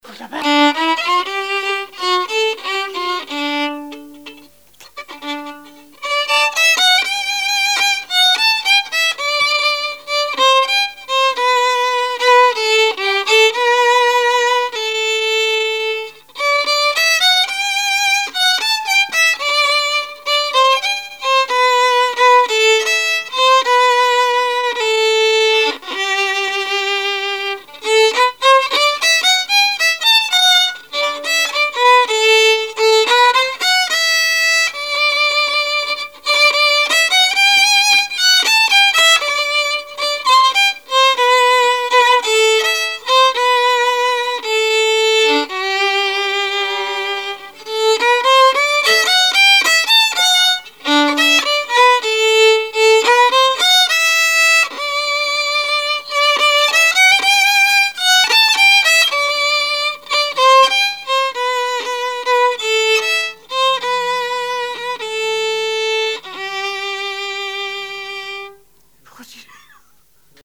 musique varieté, musichall
Répertoire musical au violon
Pièce musicale inédite